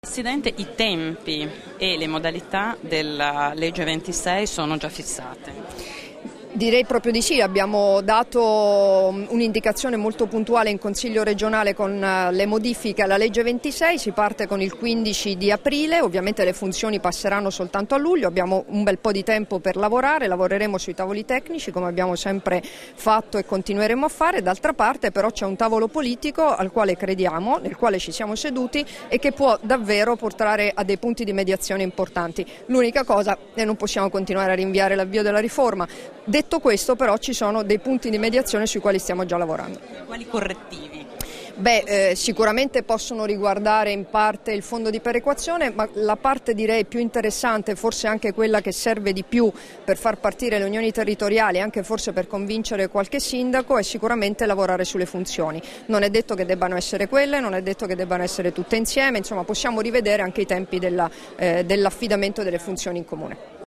Dichiarazioni di Debora Serracchiani (Formato MP3) [1019KB]
a margine della tavola rotonda "Le aggregazioni tra Comuni", rilasciate a Tavagnacco il 12 marzo 2016